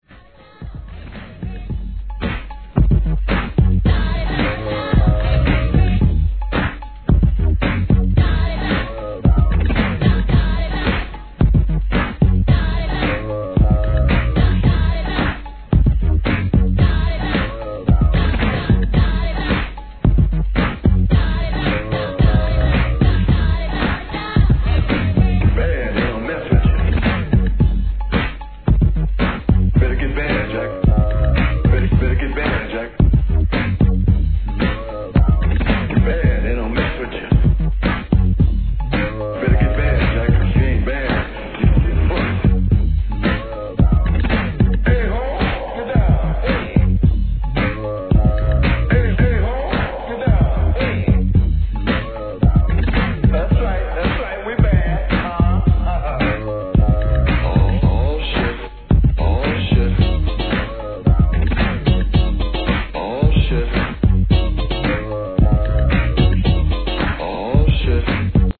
HIP HOP/R&B
古き良きスクラッチ、サンプラーに温故知新を思います。